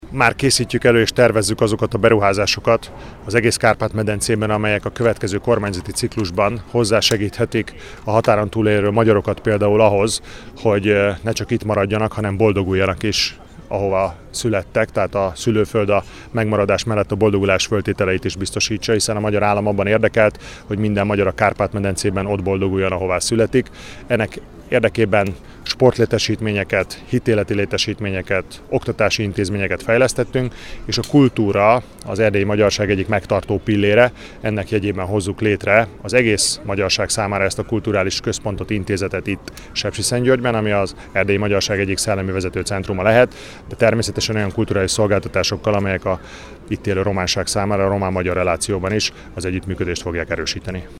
Lázár János szerdán a Sepsiszentgyörgy főterén tartott sajtótájékoztatóján kiemelte: a pénz egy részét már átutalták a Sapientia Alapítványon keresztül.